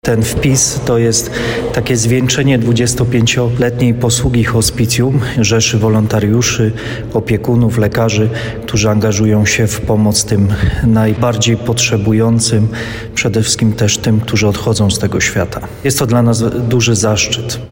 14 czerwca podczas uroczystej Sesji Rady Miejskiej w Żywcu dokonano wpisów do Złotej Księgi.